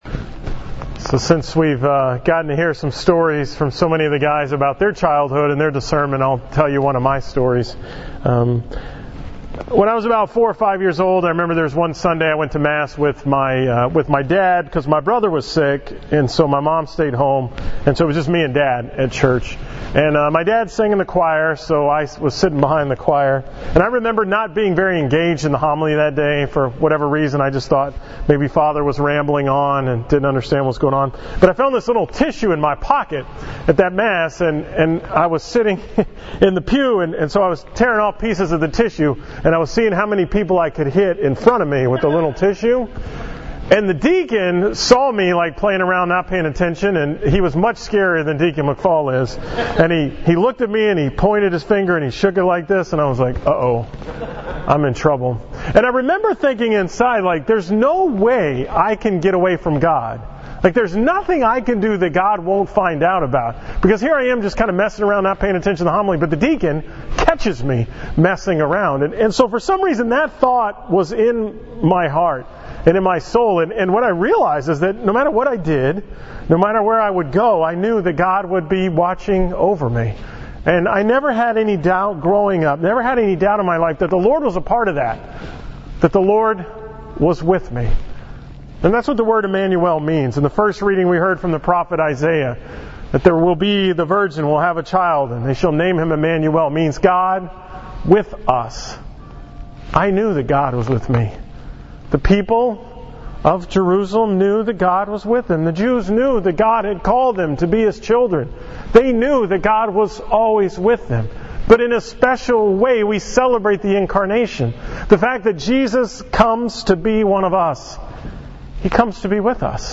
From the Men's Discernment Retreat at St. Mary's Seminary on December 18, 2016